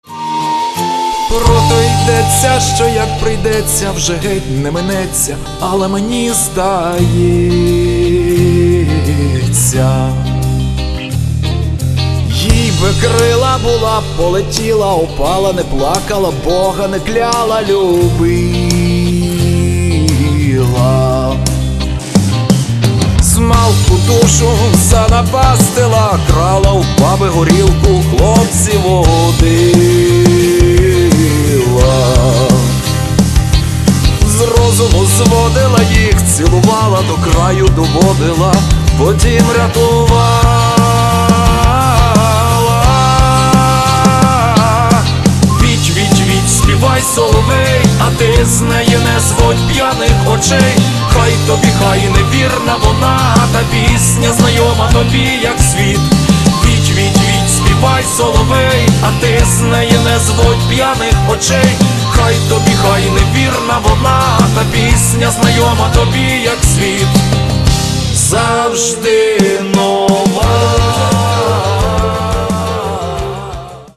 Каталог -> Рок и альтернатива -> Фольк рок